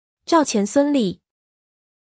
• ピンイン: zhào qián sūn lǐ